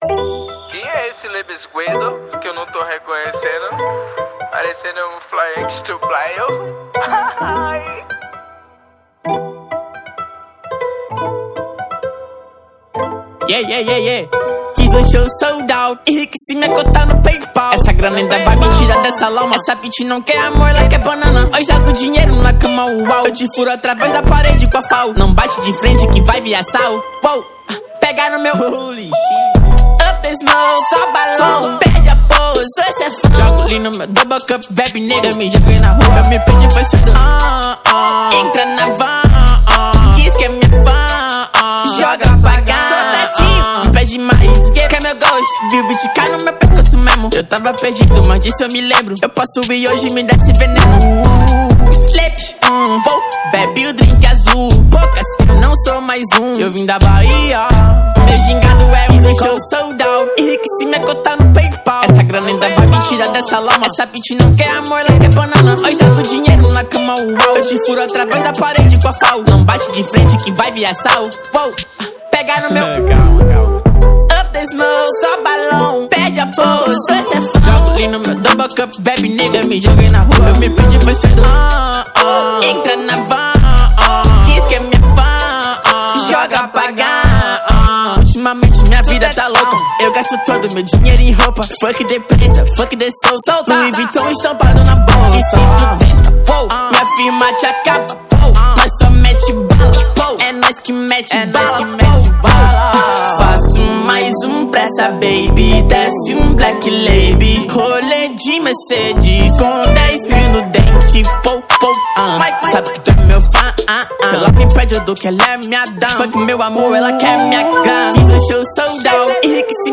2025-02-14 20:59:22 Gênero: Hip Hop Views